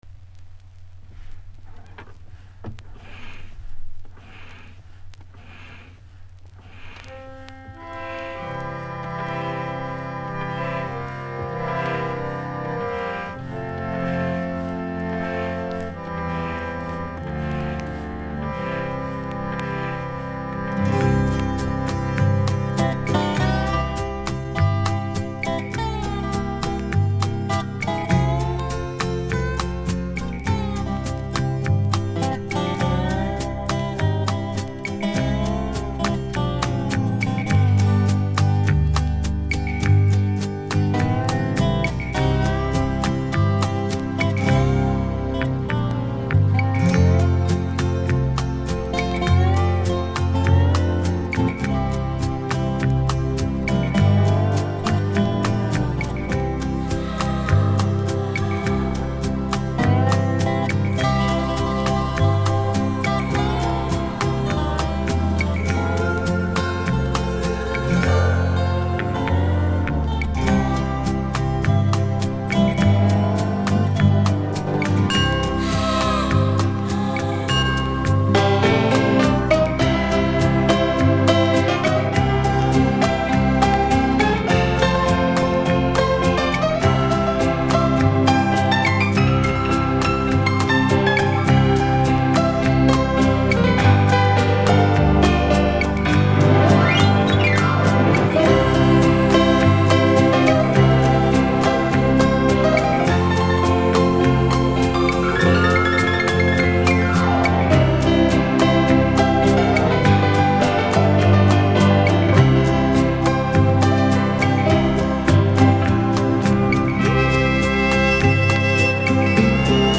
Эта мелодия похожа на исполнение в стиле "кантри".